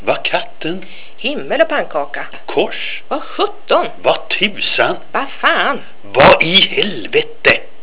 Для прослушивания или скачивания звукового файла, содержащего произношение приведенных примеров, пожалуйста, нажмите на название соответствующего раздела.